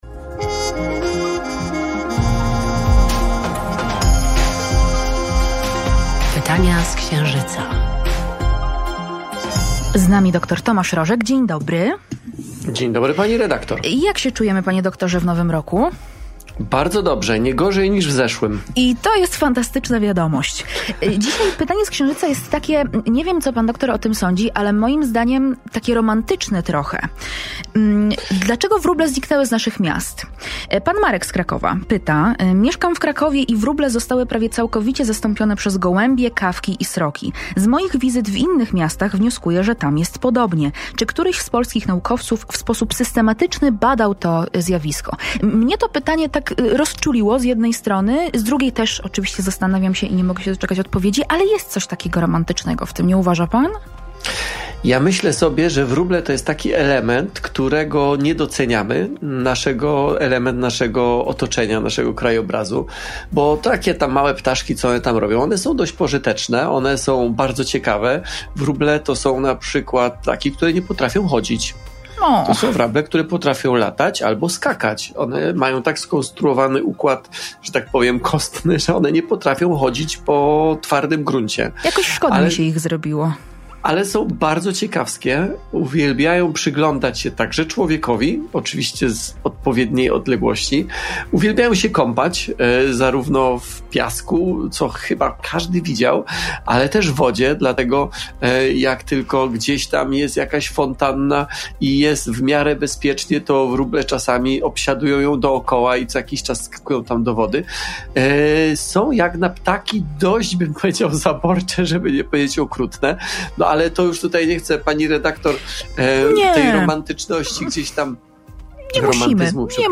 Gdzie się podziały wróble - odpowiada doktor Tomasz Rożek